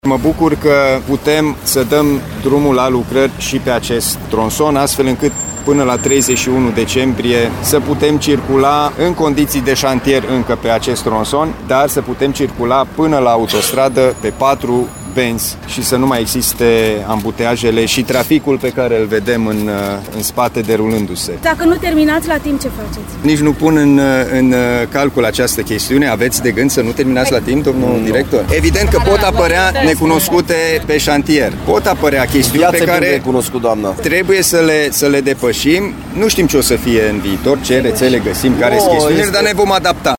Lucrările vor fi gata în termen, dă asigurări președintele Consiliului Județean Timiș, Alin Nica.